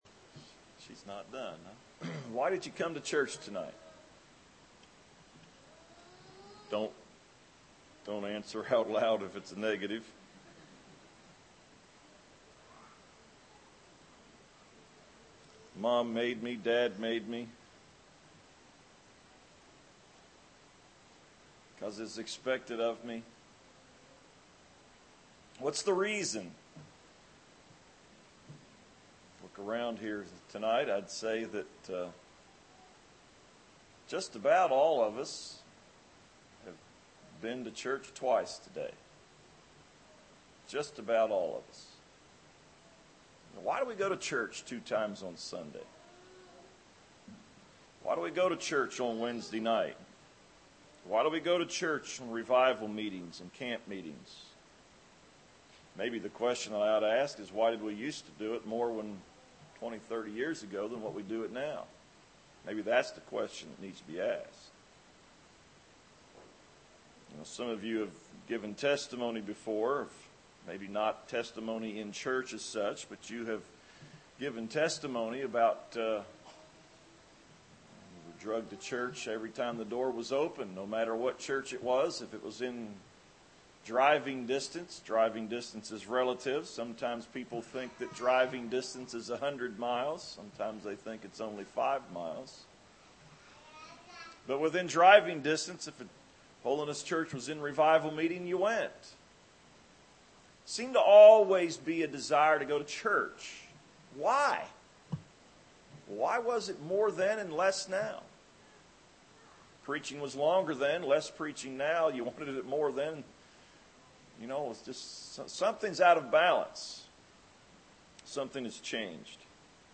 Seven Reasons We Should Assemble – Weisbach Church